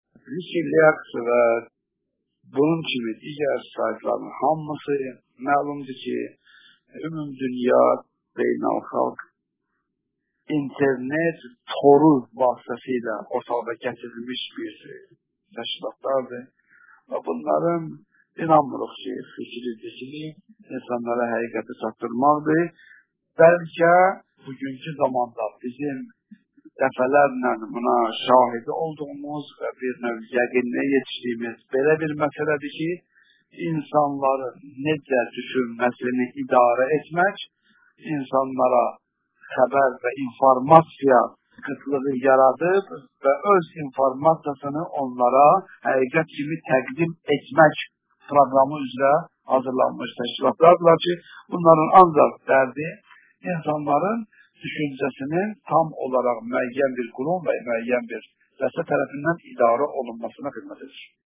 Radio Verilişləri